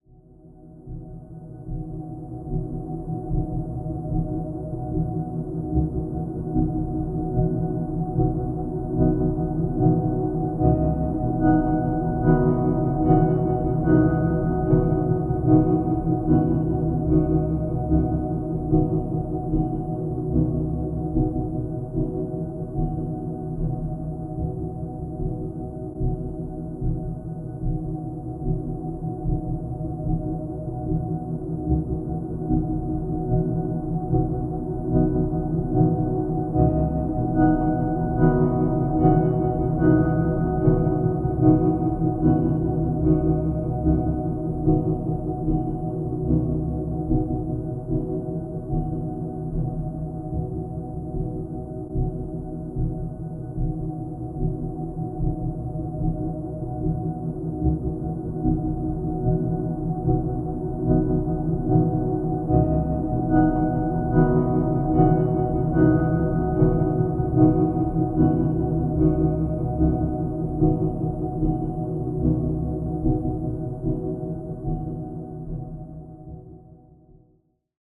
Speed 50%